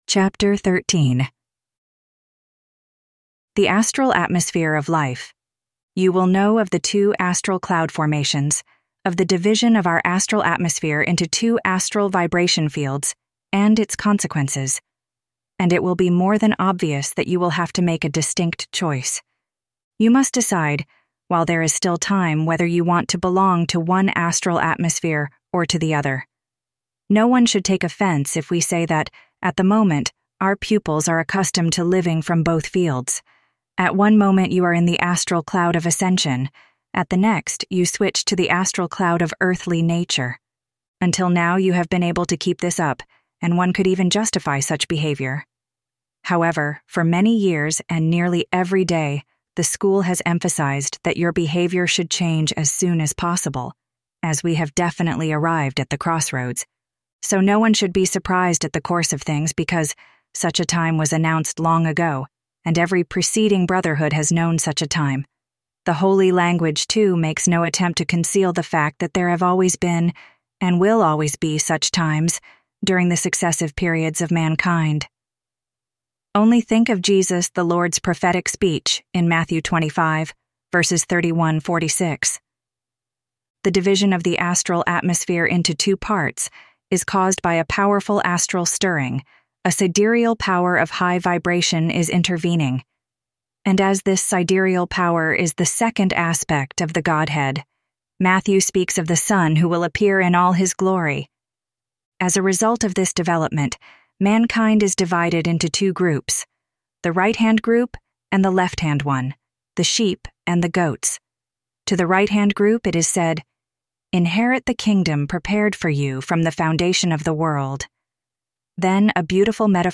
Audio Books of the Golden Rosycross